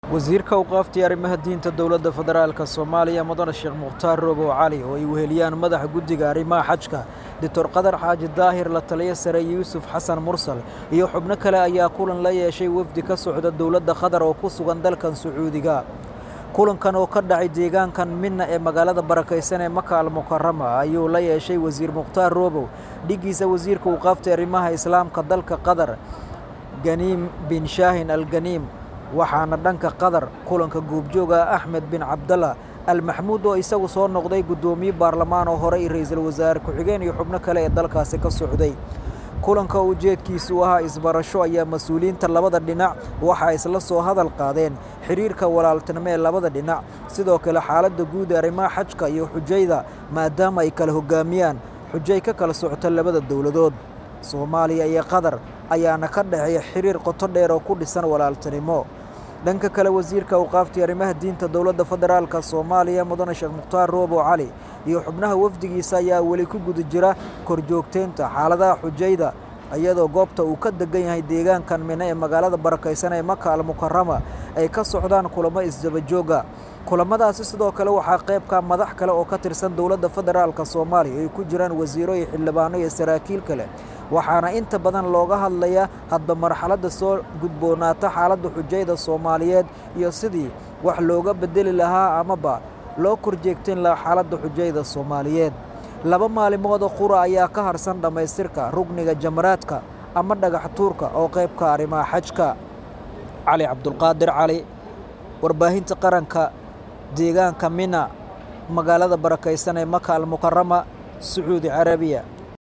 Halkaan Hoose Ka Dhageyso kulanka labada Wasiir ee ka dhacay magaaladda Mina ee dalka Sacuudi Carabiya.